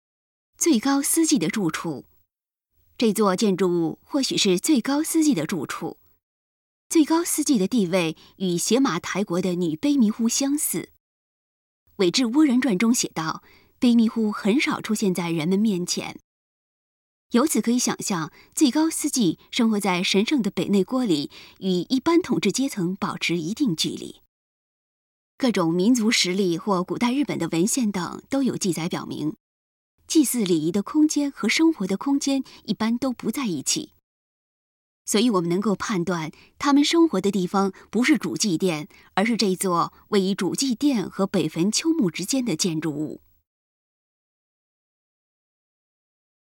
语音导览 前一页 下一页 返回手机导游首页 (C)YOSHINOGARI HISTORICAL PARK